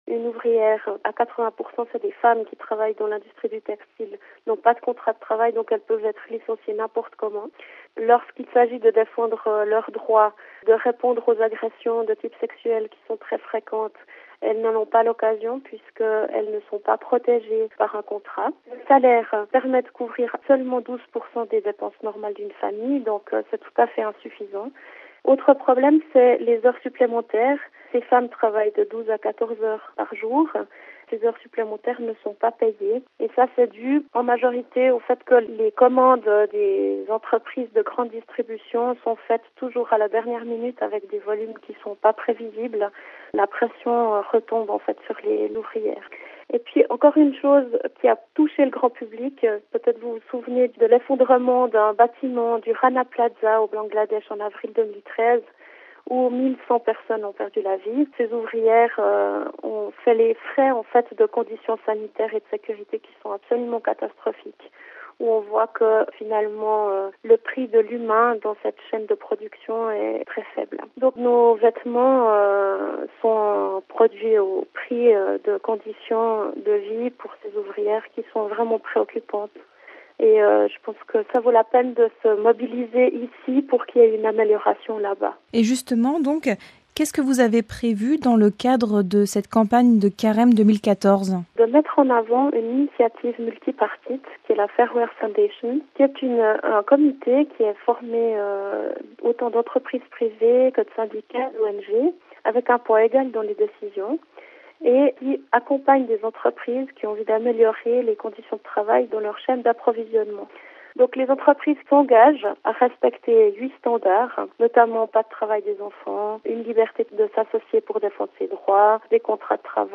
(RV) Entretien - En Suisse, la campagne de Carême des organisations Action de Carême, Pain pour le Prochain et Étre partenaires débute le mercredi des Cendres.